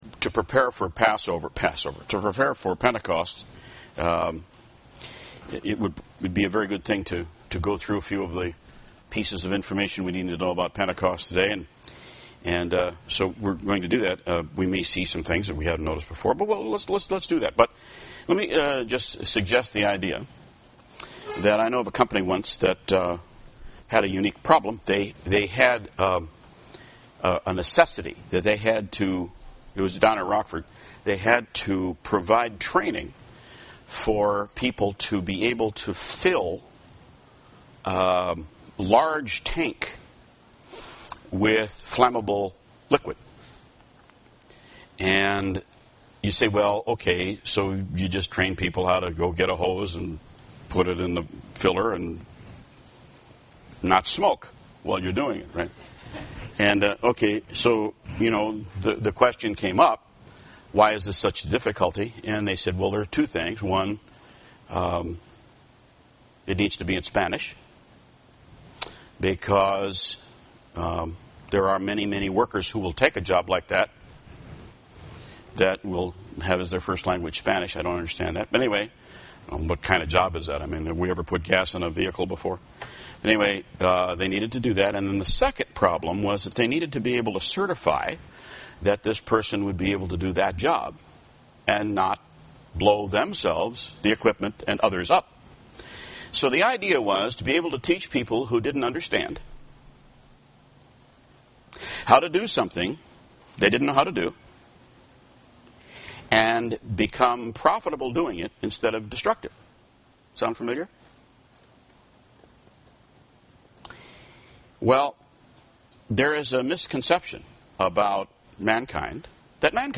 UCG Sermon Notes Intro: The people of this earth were born into this evil world – they don’t know of a better world with which to compare it.